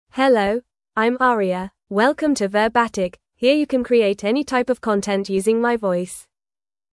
FemaleEnglish (United Kingdom)
Aria is a female AI voice for English (United Kingdom).
Voice sample
Aria delivers clear pronunciation with authentic United Kingdom English intonation, making your content sound professionally produced.